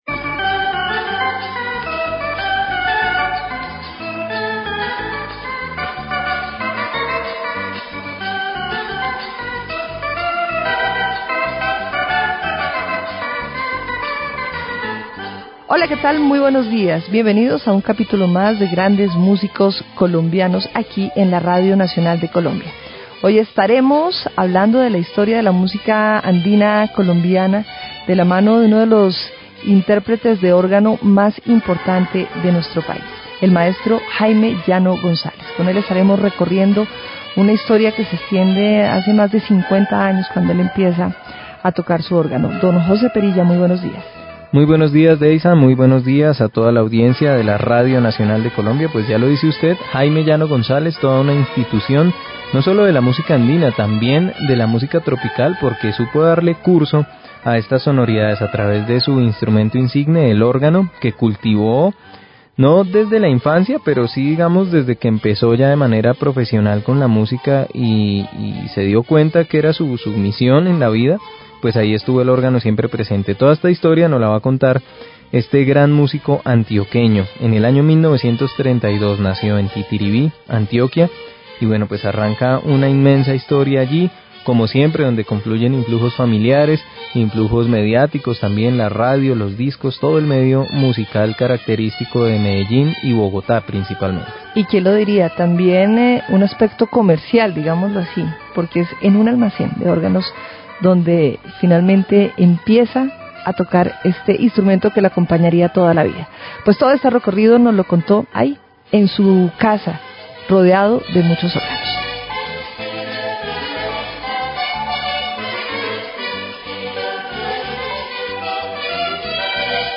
Señal Memoria conserva una de las últimas entrevistas de Jaime Llano.